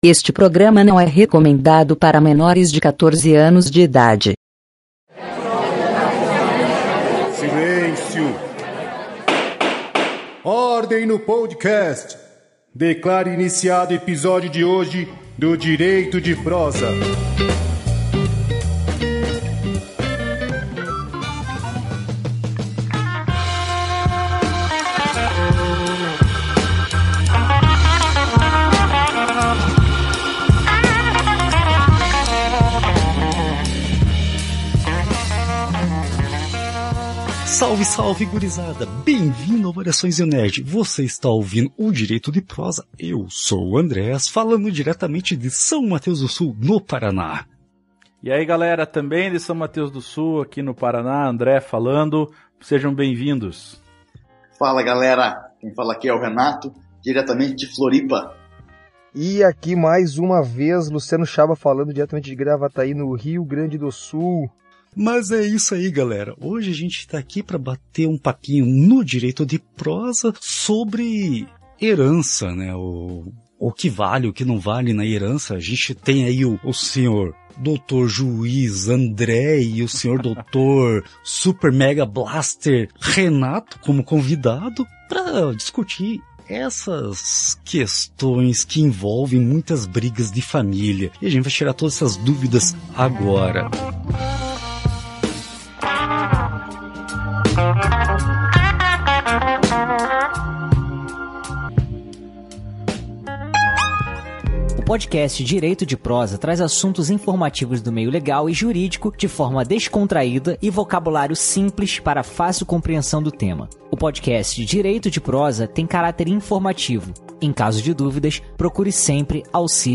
Nesse episódio batemos um papo com um juiz de direito e um advogado sobre heranças.Vamos tirar algumas dúvidas recorrentes sobre esse assunto e alguns mitos ou verdades sobre esse tema que geralmente causam conflitos familiares.
O podcast “Direito de Prosa” é um dos programas da família Variações de um Nerd, onde tratamos de assuntos e temas do meio jurídico de forma leve e linguagem mais simples, e sempre com especialistas para trazer propriedade e veracidade ao conteúdo.